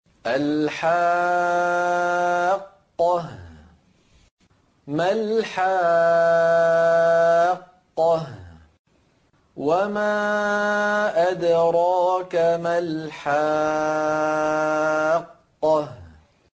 3 - När man flytter från en madd-bokstav till den första bokstaven i den stressade, som i: